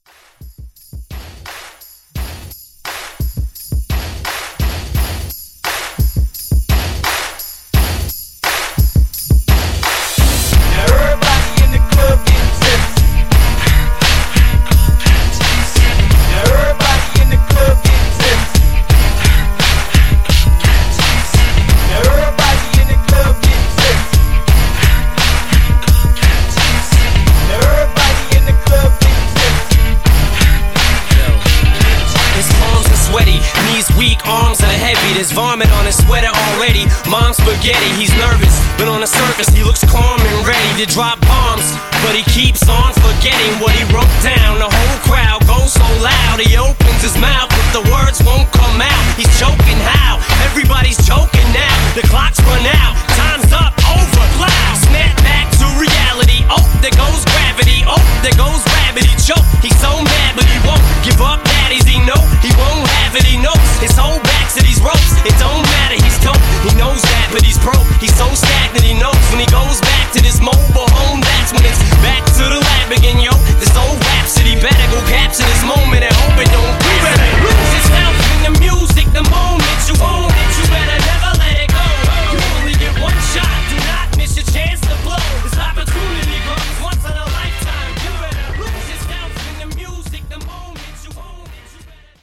Genre: 70's
Clean BPM: 125 Time